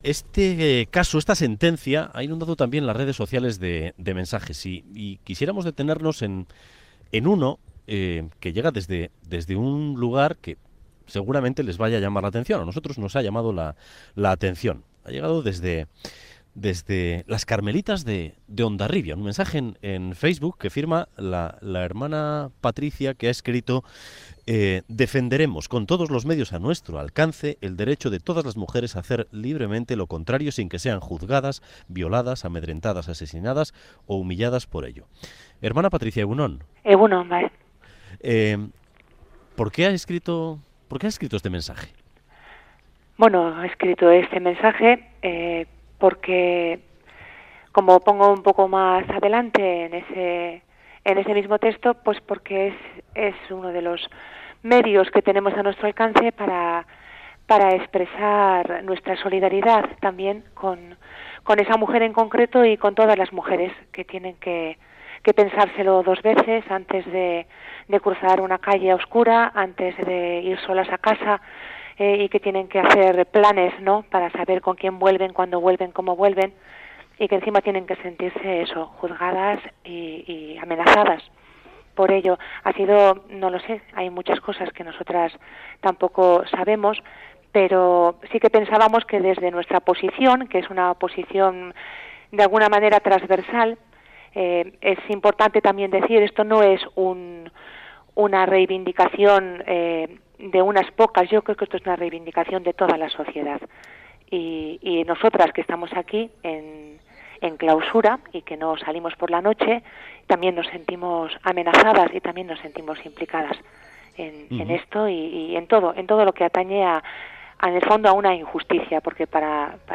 Audio: Las monjas de la localidad guipuzcoana publican en redes sociales un alegato en apoyo de la víctima de 'La Manada. En Boulevard de Radio Euskadi hablamos con ellas.